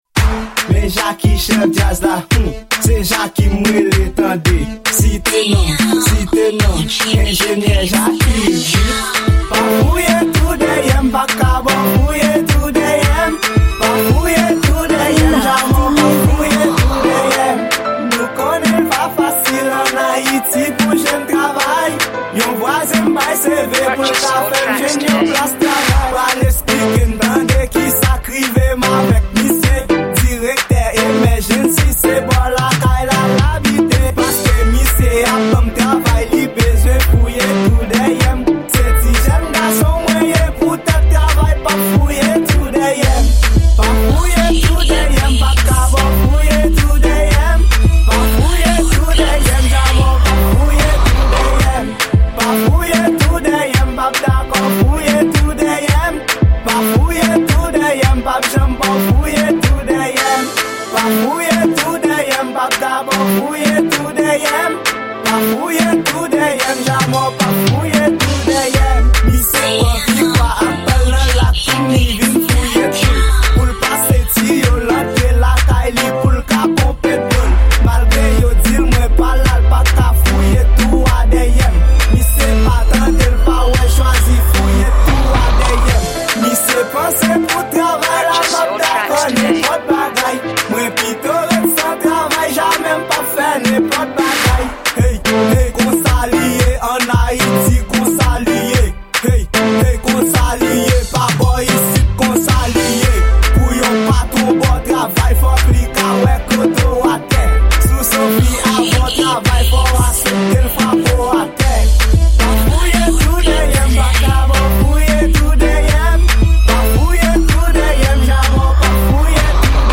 Genre: Raboday.